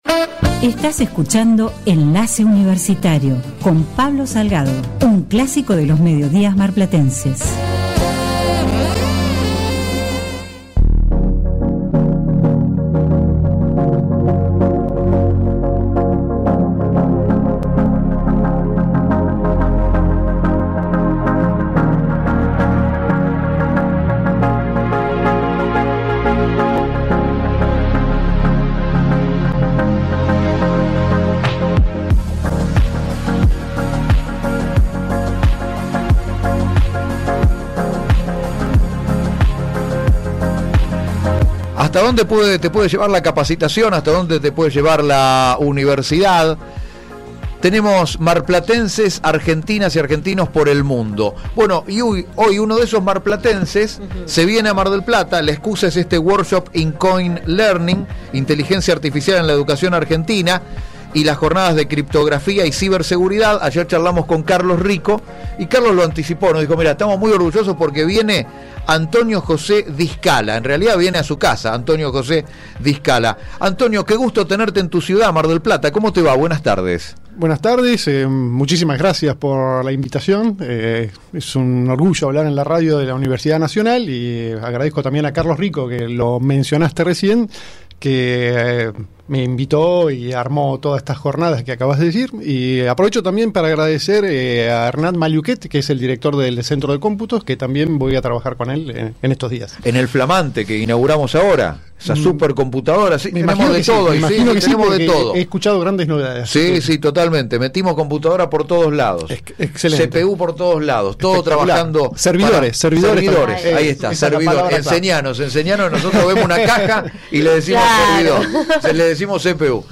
Intervista Radio Universidad Nacional de Mar del Plata